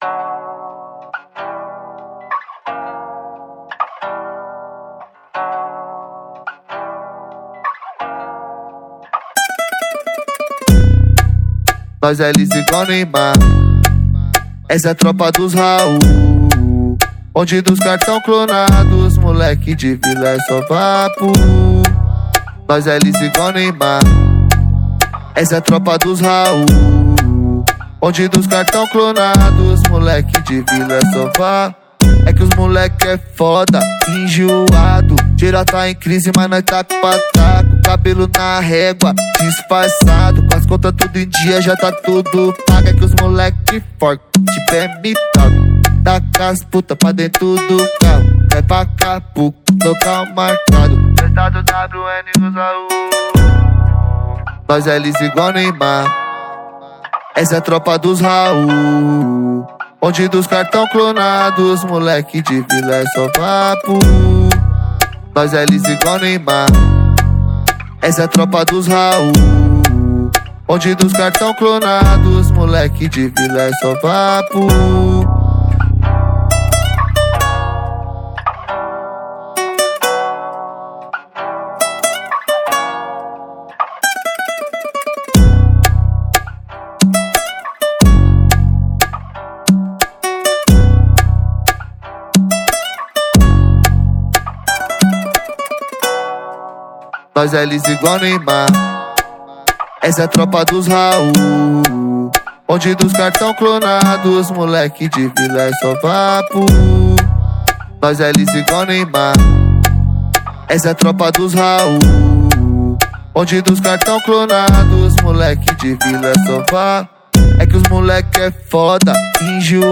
2024-06-16 09:43:07 Gênero: Funk Views